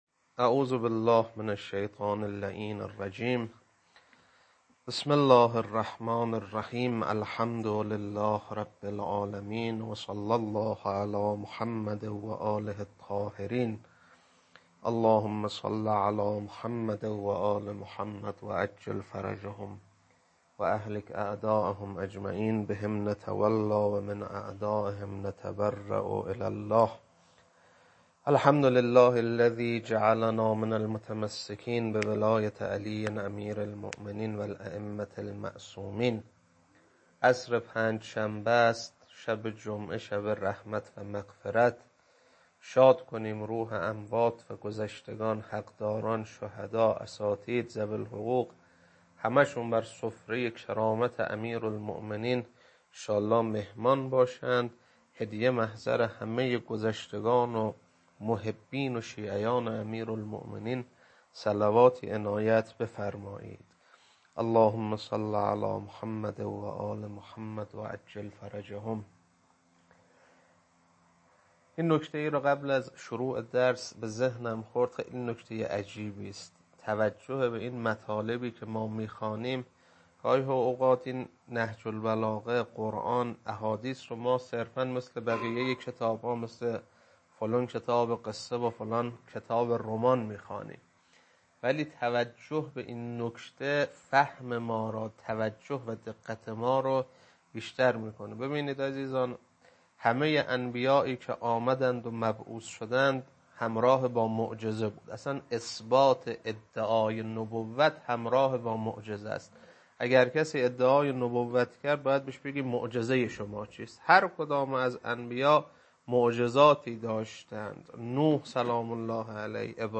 خطبه 26.mp3